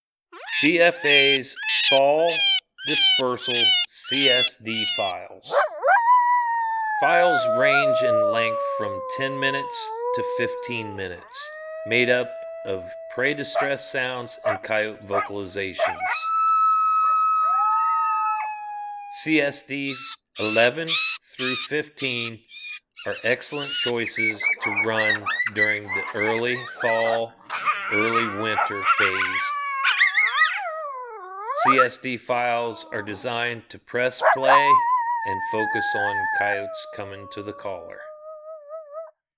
V = Vocals
Each BFA Spring CSD File is made up of our most popular Coyote Howls, Coyote Social Vocalizations, Coyote fights and Prey Distress Files.